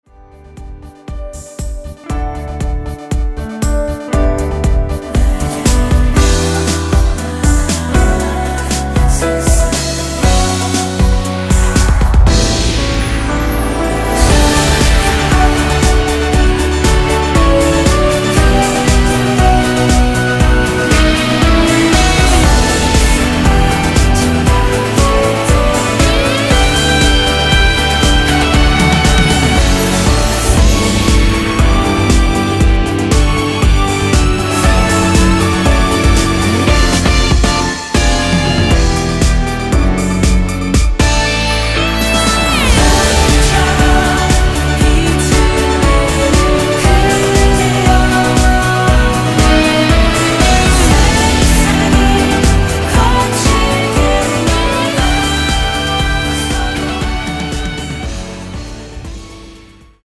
원키에서(-1)내린 코러스 포함된 MR입니다.(미리듣기 확인)
앞부분30초, 뒷부분30초씩 편집해서 올려 드리고 있습니다.
중간에 음이 끈어지고 다시 나오는 이유는